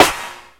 Claps
Amazin' Clap.wav